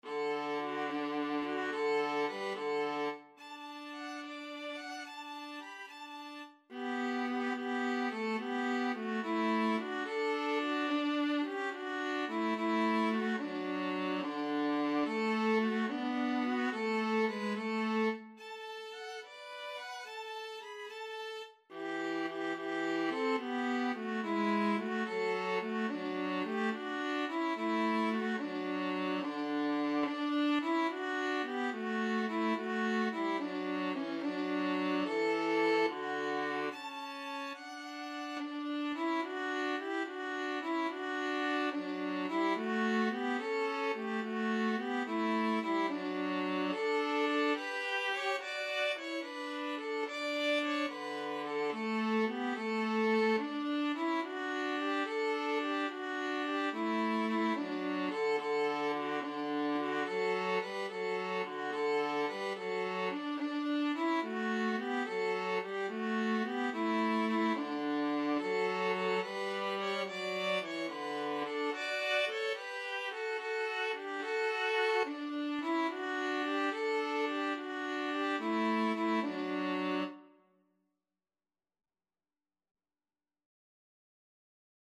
Free Sheet music for Violin-Viola Duet
D major (Sounding Pitch) (View more D major Music for Violin-Viola Duet )
6/8 (View more 6/8 Music)
Maestoso . = c. 72
Classical (View more Classical Violin-Viola Duet Music)